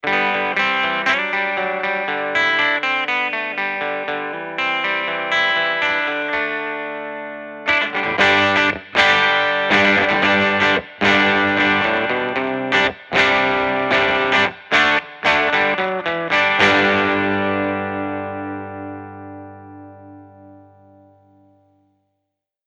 Échantillons sonores Audio Technica AE-5100
Audio Technica AE5100 - gitara elektryczna